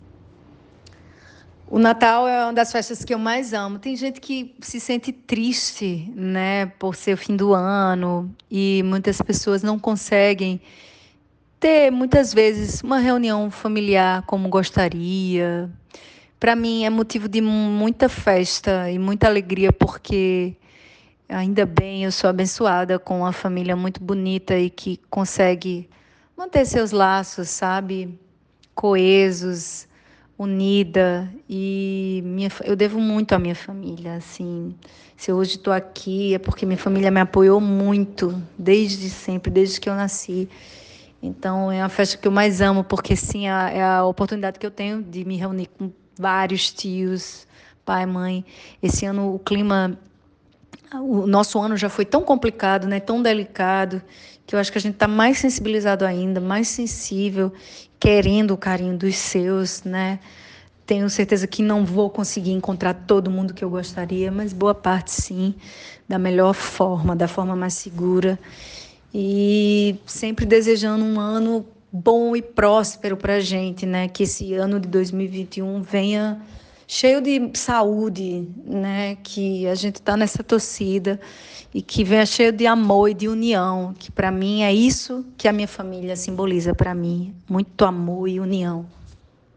Confira um trecho da entrevista da cantora e atriz no programa